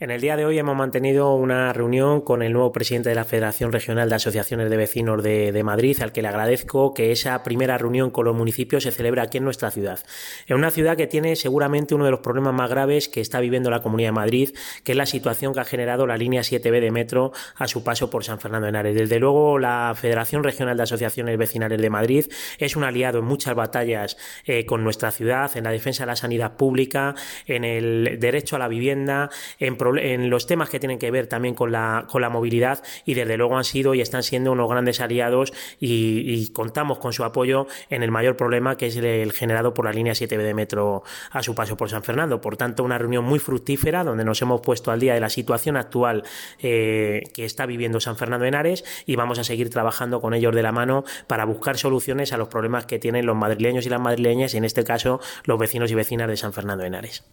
Javier Corpa, alcalde de San Fernando de Henares
DeclaracionesJaviCorpaReunionFRAMV.mp3